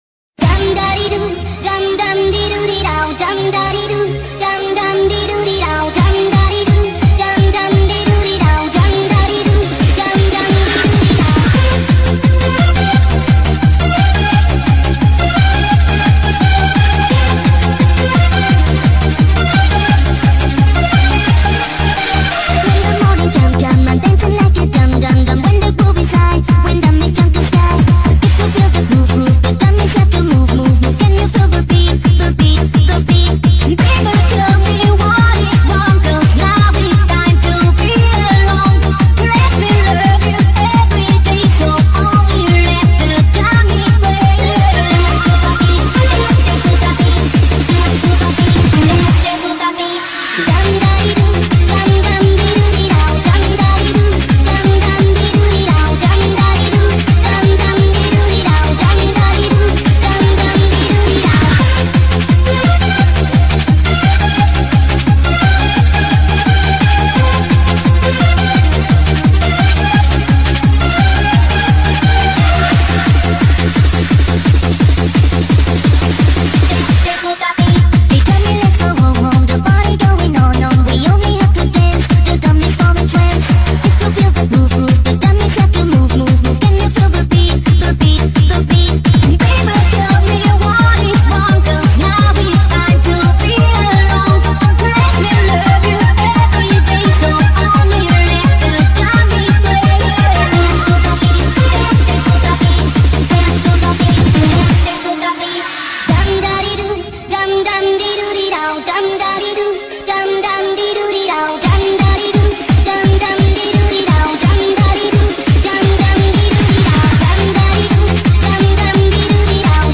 BPM86-172